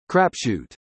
• US /ˈkræpˌʃut/
• UK /ˈkræpʃu:t/